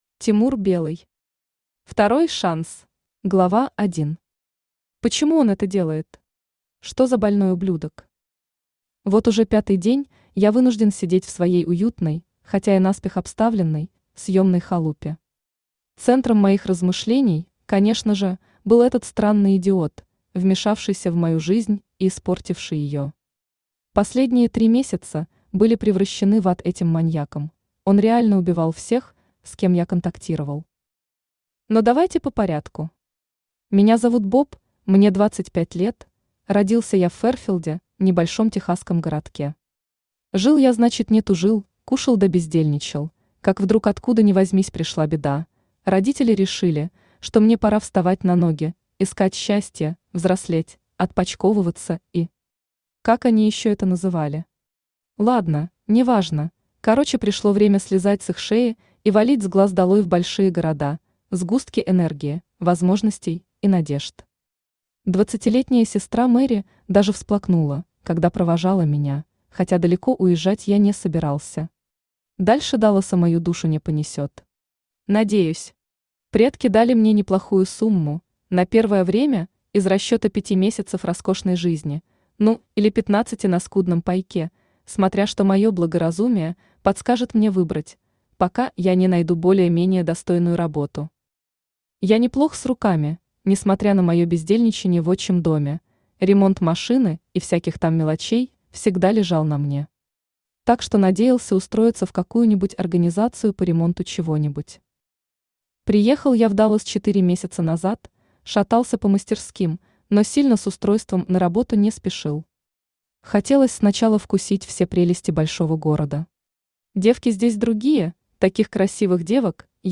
Аудиокнига Второй шанс | Библиотека аудиокниг
Aудиокнига Второй шанс Автор Тимур Белый Читает аудиокнигу Авточтец ЛитРес.